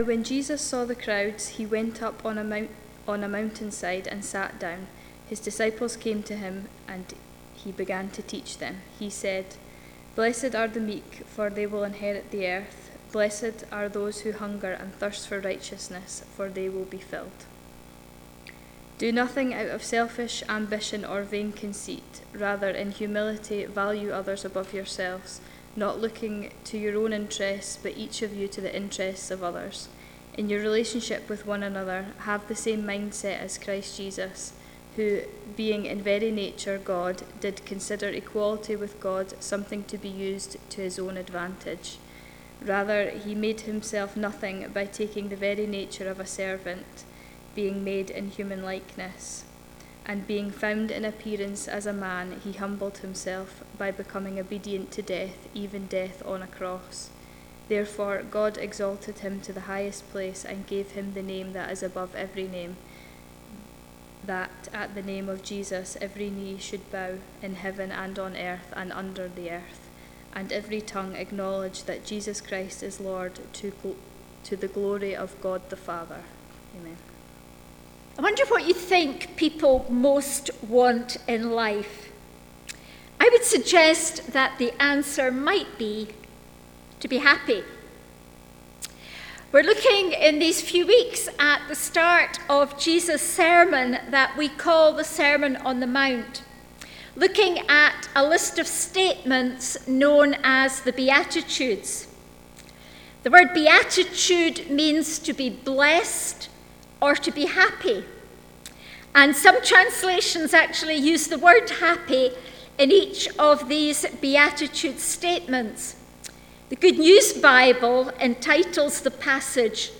Series: Beatitudes Passage: Matthew 5:1,2,5,6 , Philippians 2:3-11 Service Type: Sunday Morning